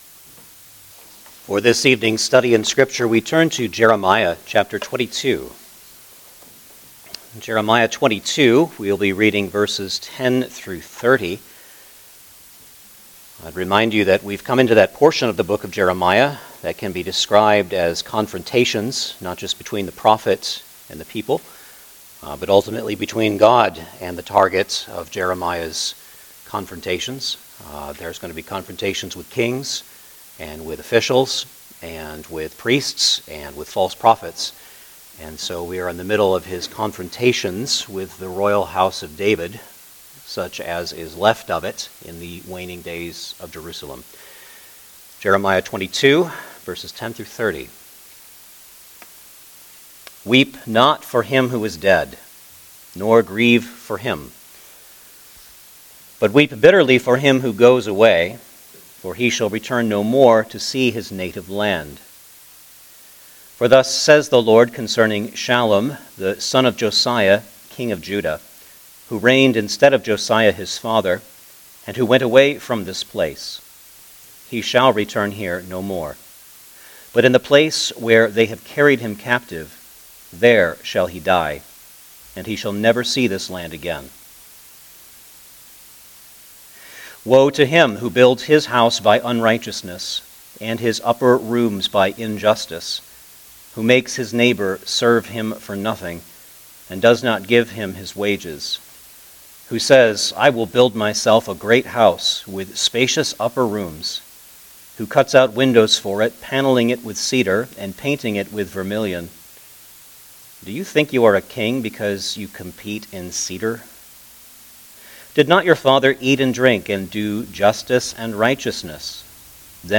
Jeremiah Passage: Jeremiah 22:10-30 Service Type: Sunday Evening Service Download the order of worship here .